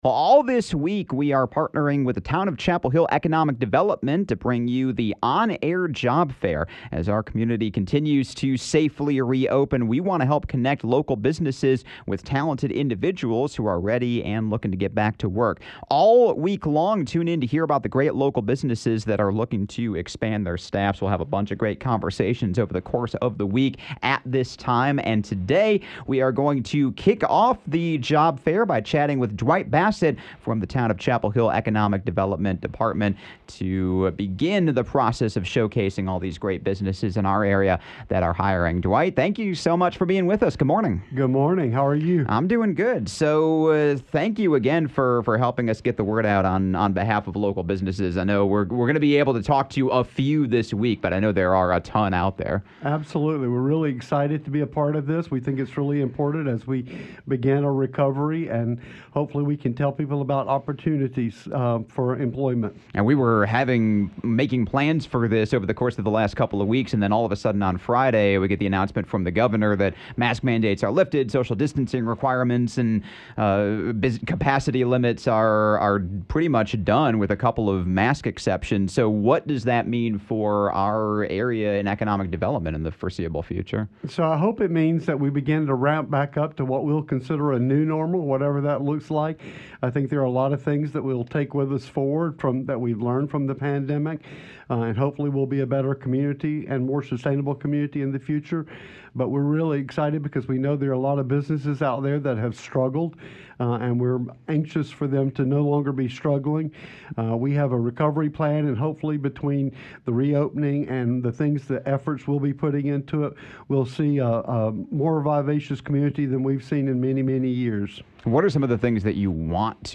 Tune in to the On-Air Job Fair at 7:30am and 5:30pm every day beginning Monday, May 17th through May 21st for exclusive interviews with local business owners looking to hire.